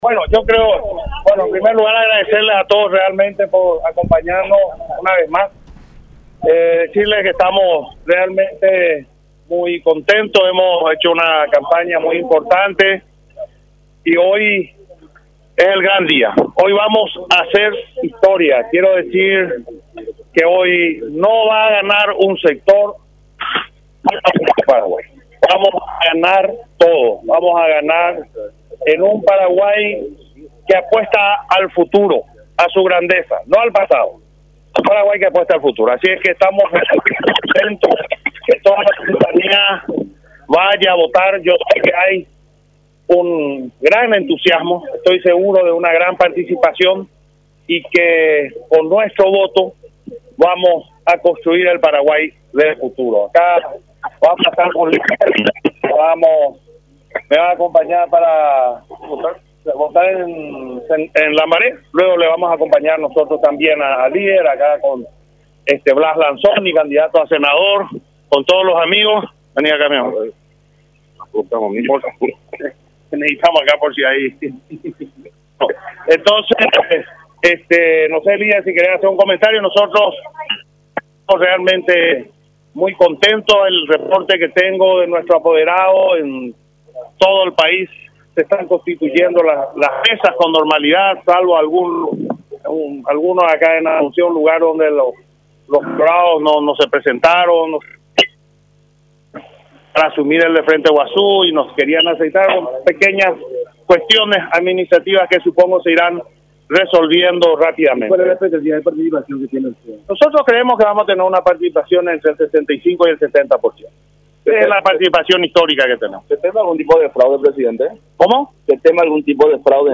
“Nosotros creemos que tendremos una participación del 65% al 70%. Será una participación histórica”, expresó el postulante en la conferencia de prensa realizada en su residencia, apuntando que el ciudadano tiene la libertad de ejercer el voto de la mejor manera que le motive.
09-CONFERENCIA-DE-EFRAIN-ALEGRE.mp3